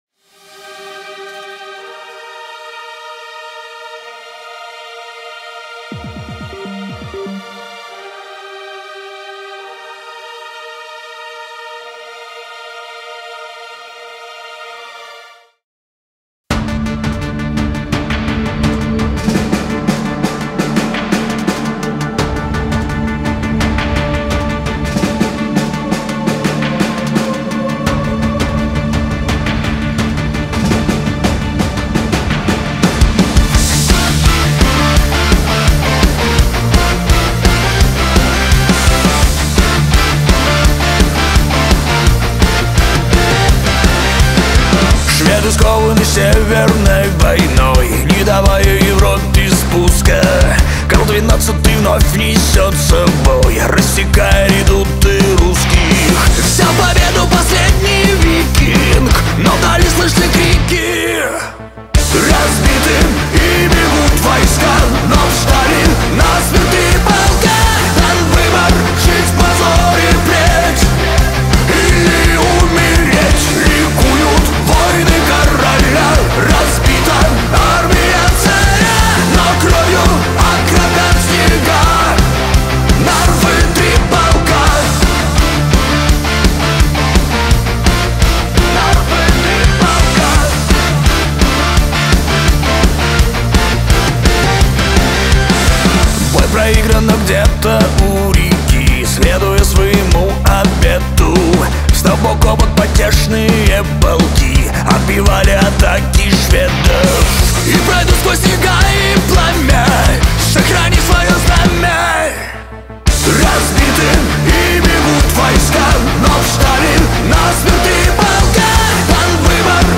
Альбом: Rock